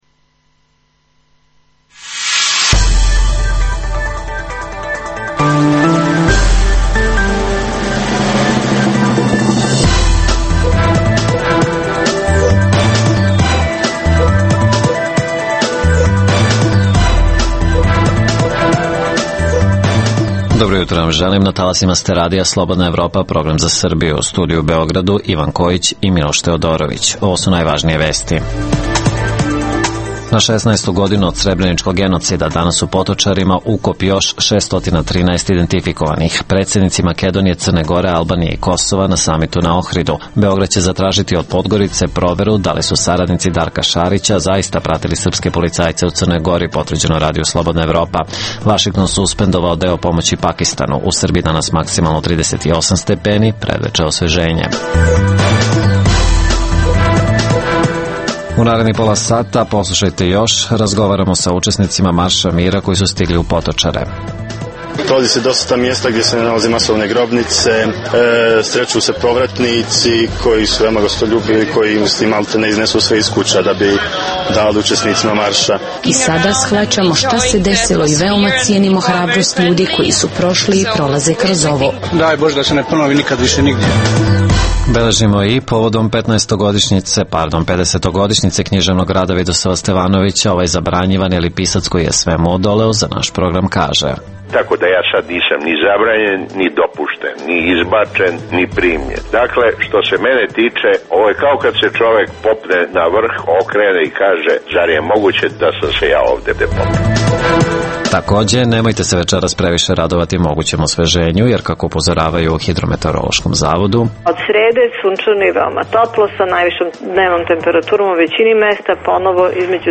U ovoj emisiji možete čuti: - Na šesnaestu godinu od srebreničkog genocida ukop još 613 identifikovanih. - Izveštavamo sa lica mesa.